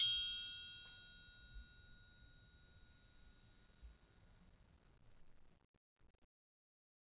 Triangle3-Hit_v1_rr2_Sum.wav